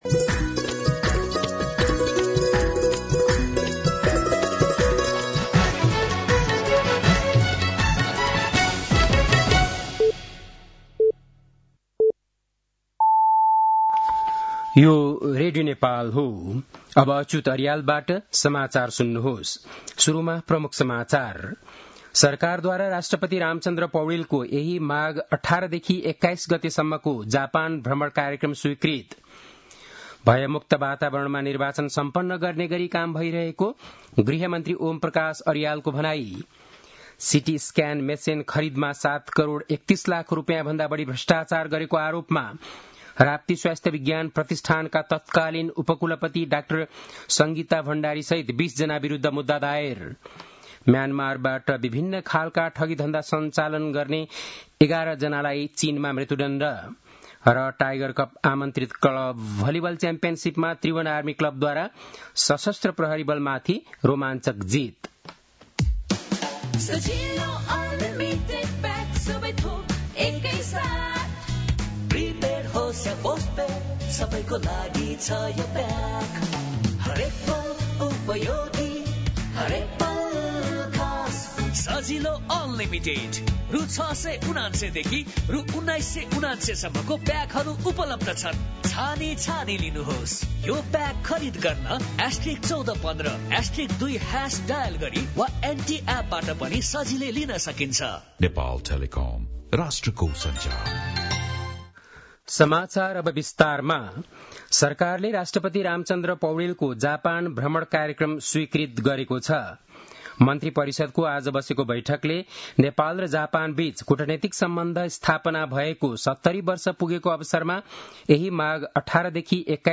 बेलुकी ७ बजेको नेपाली समाचार : १५ माघ , २०८२
7-pm-nepali-news-1-2.mp3